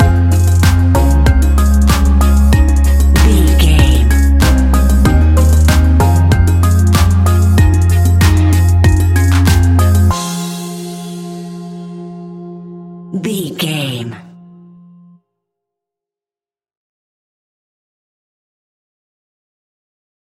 Ionian/Major
F♯
house
electro dance
synths
techno
trance